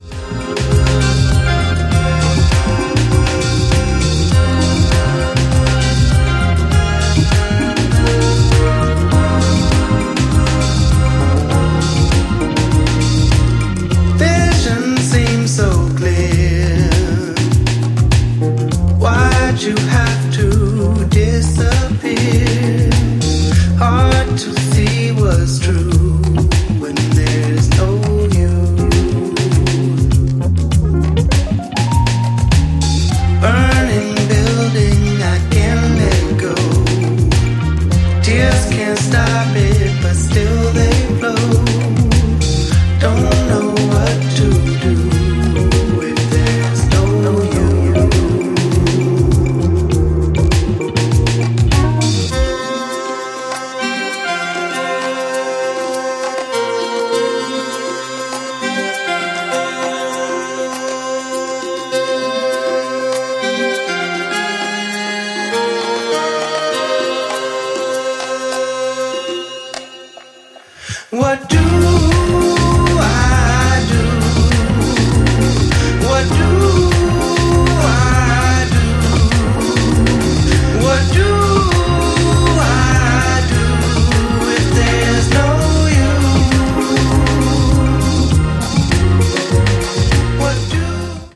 ジャンル(スタイル) NU DISCO / DISCO / BALEARICA / EDITS